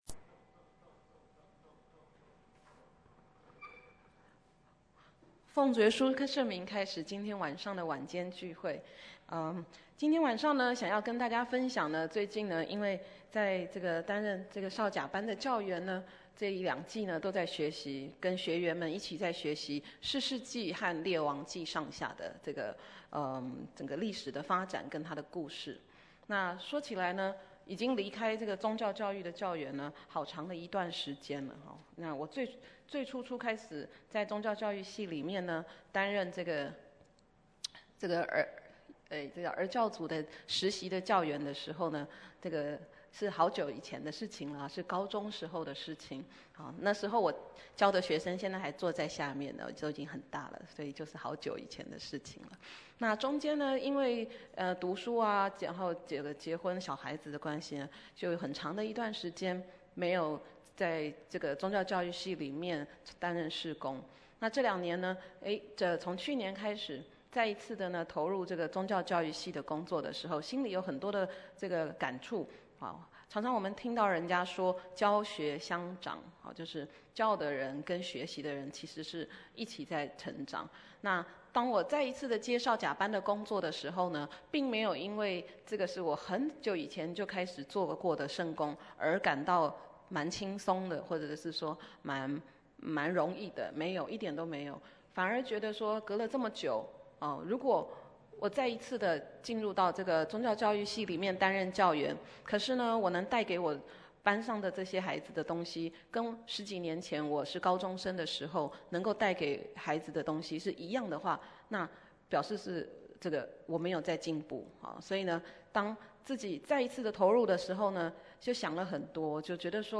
屈膝-講道錄音